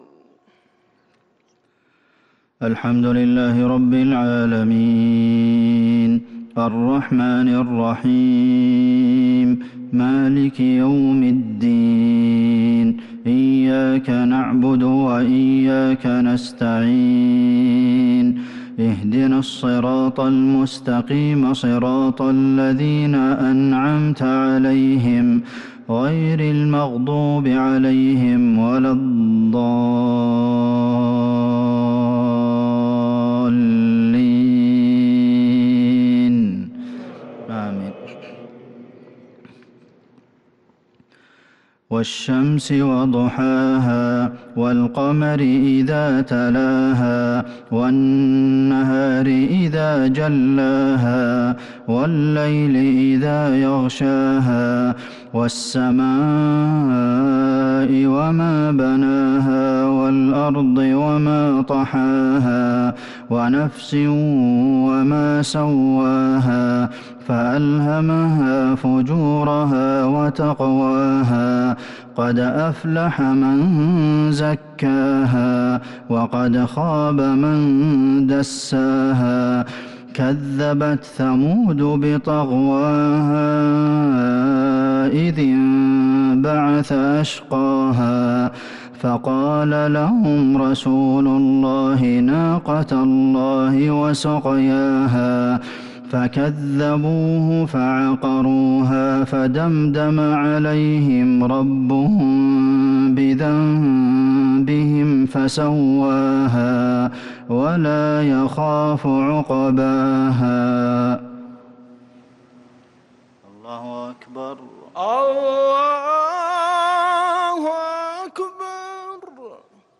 مغرب الأربعاء 6-8-1443هـ سورتي الشمس و الهمزة | Maghrib prayer Surah Ash-Shams and Al-Humazah 9-3-2022 > 1443 🕌 > الفروض - تلاوات الحرمين